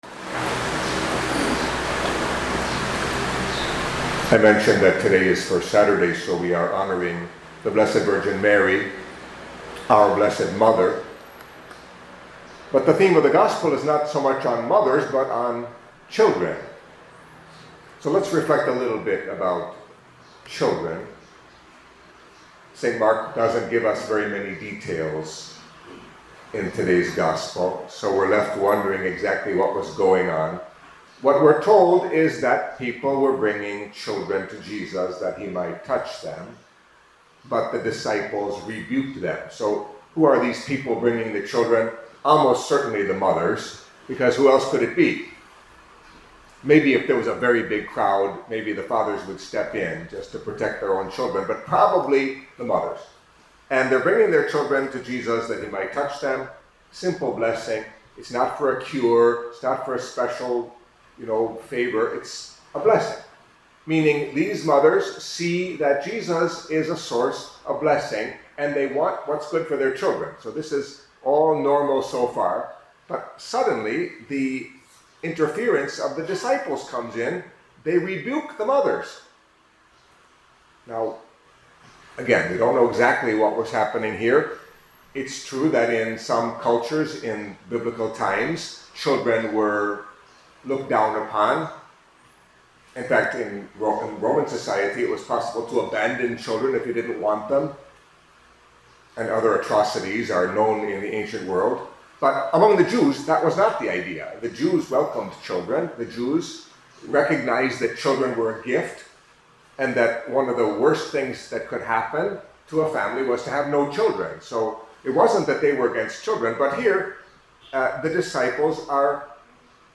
Catholic Mass homily for Saturday of the Seventh Week in Ordinary Time